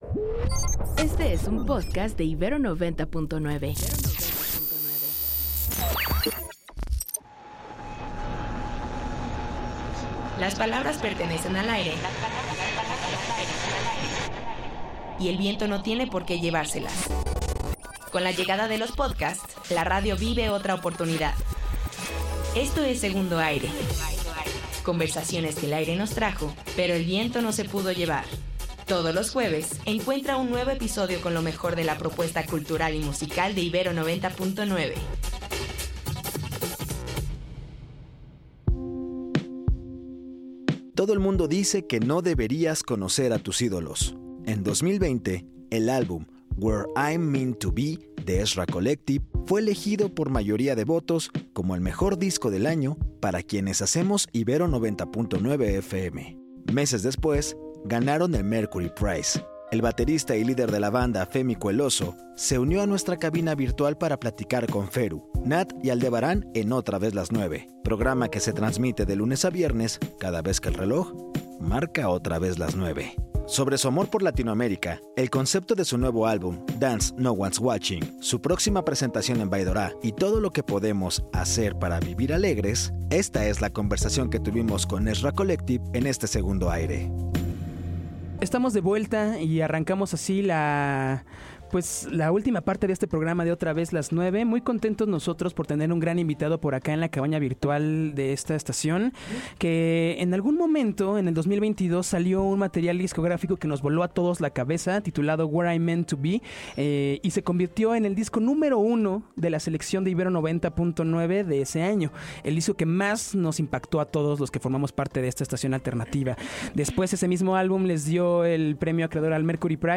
Le damos un Segundo Aire a la conversación que tuvimos con Ezra Collective.